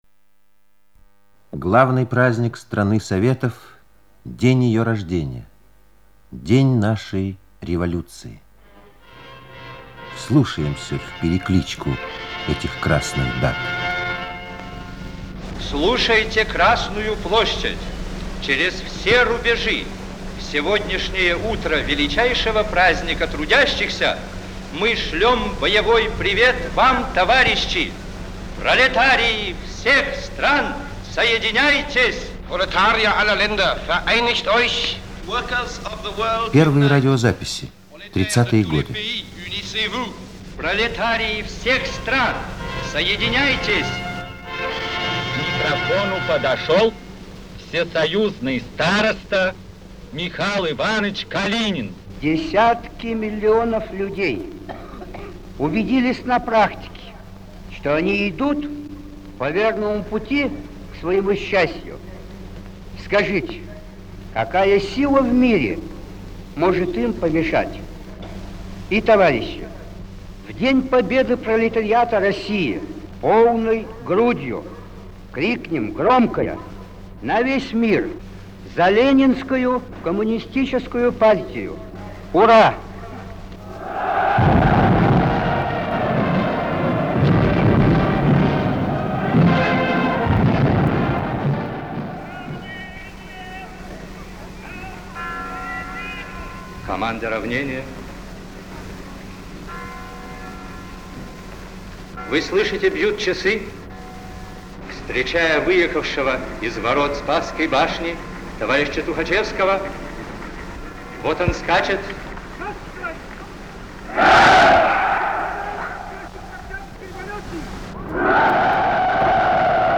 А ленинский голос идёт впереди колонн.
1933 год. Над площадью ломкий, выветренный годами голос К.Э.Циолковского: 'Из тихого, скромного горда Калуги позвольте обратиться к вам, участники рабочих и колхозных колонн, марширующие по Красной площади┘ Недавно я сделал открытие, которое сделает уже вас свидетелями заатмосферных путешествий┘' 'Второй этаж площади' - ребята, восседающие на отцовских плечах, слушают непонятные еще слова: 'Юные летатели, так называю я карапузов, они, герои-смельчаки, проложат звёздные трассы'.
Вступите и вы в эту беседу времён, прислушайтесь к голосам парадов Революции.